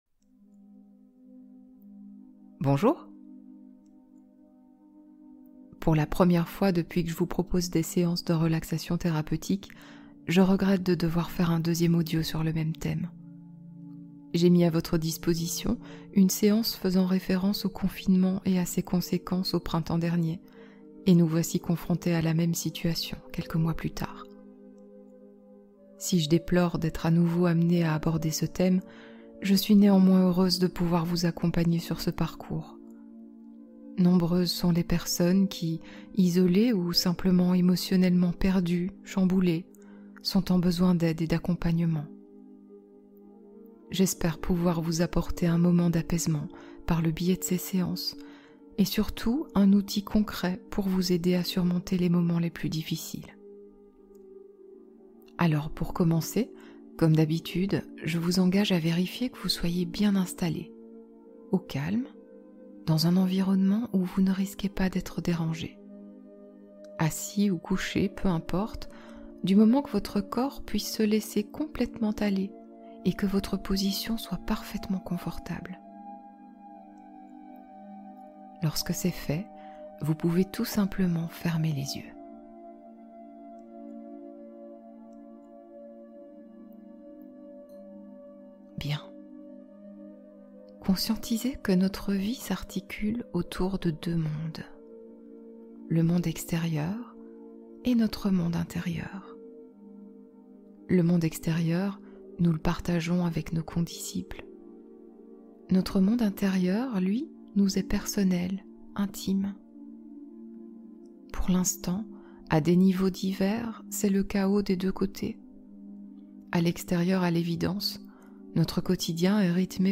Confinement : prendre soin de son moral avec hypnose